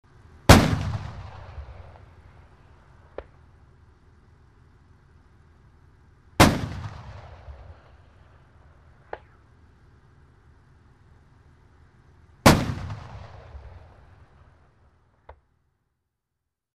Звуки танка
Грохот мощного танкового выстрела